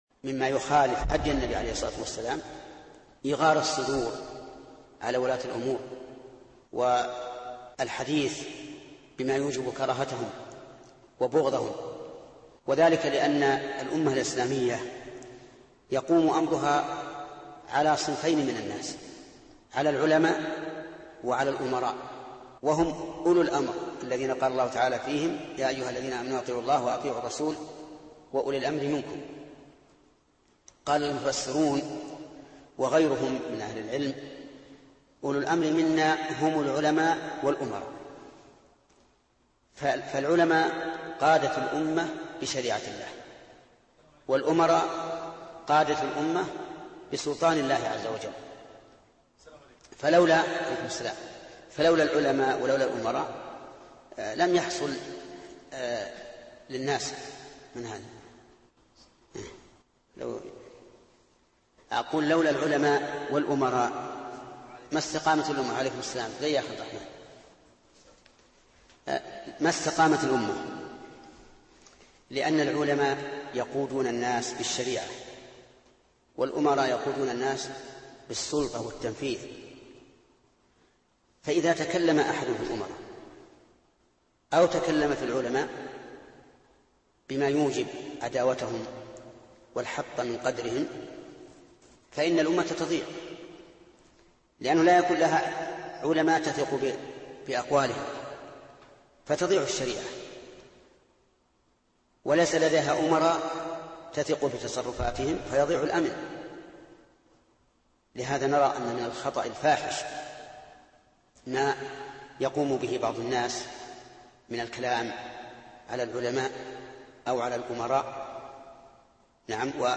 Download audio file Downloaded: 278 Played: 577 Artist: الشيخ ابن عثيمين Title: مما يخالف هدي النبي عليه الصلاة والسلام - إيغار الصدور على ولاة الأمور Length: 2:03 minutes (481.13 KB) Format: MP3 Mono 22kHz 32Kbps (VBR)